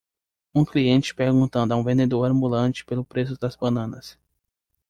Pronounced as (IPA)
/vẽ.deˈdoʁ/